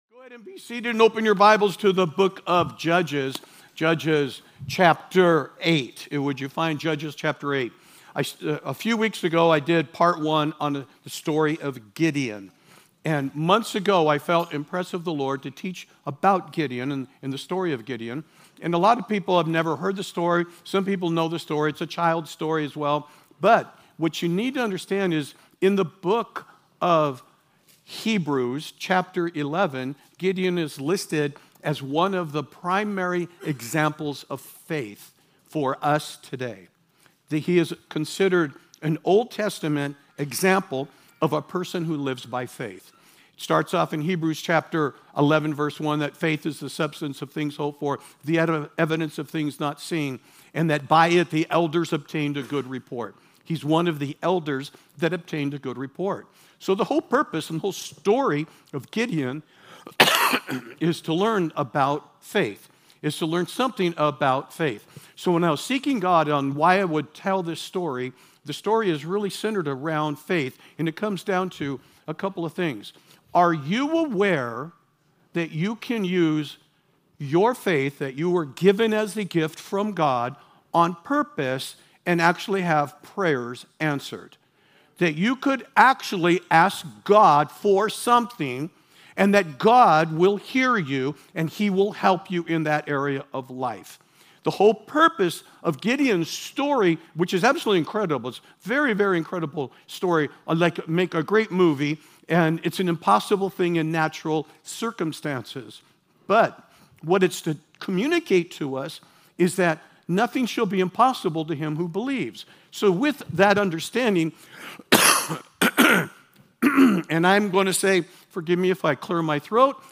Sermons | Church of Grace